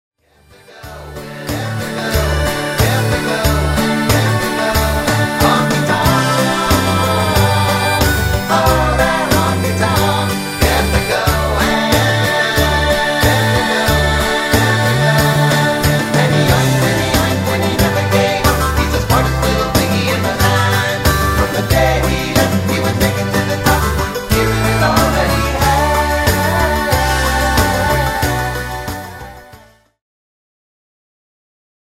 A WIDE VARIETY OF ROCK INCLUDING,